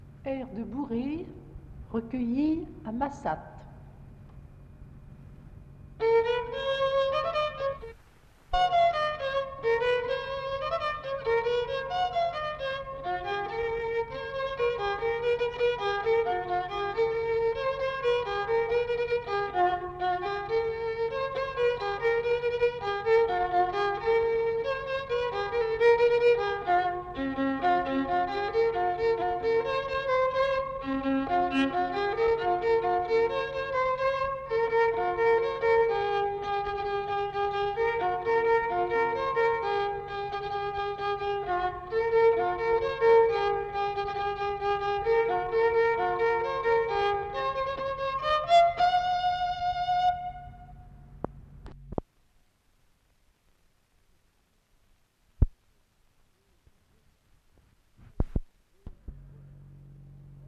Bourrée